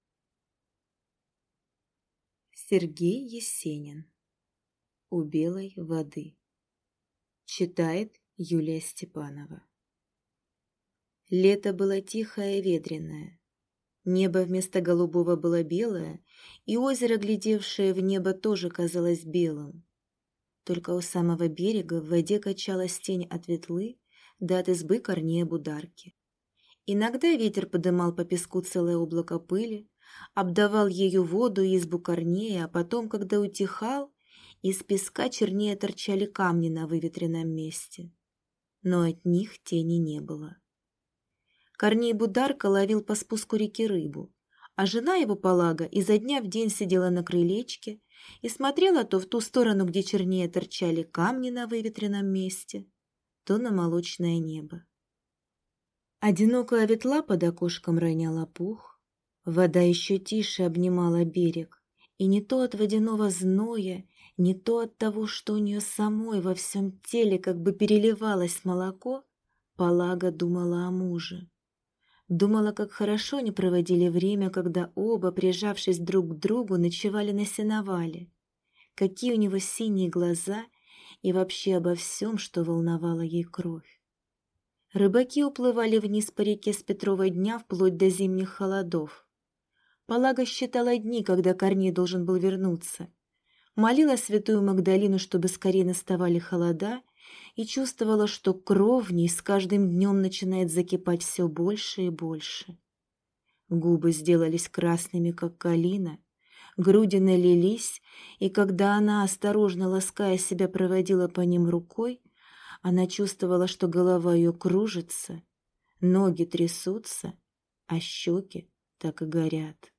Аудиокнига У Белой воды | Библиотека аудиокниг